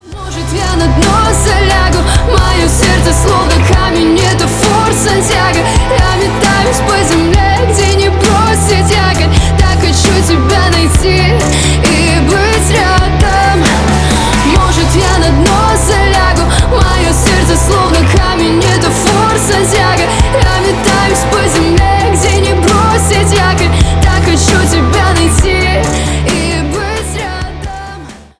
Поп Музыка
громкие